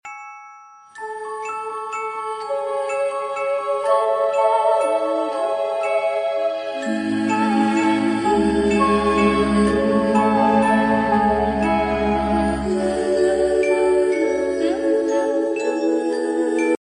just nature and peace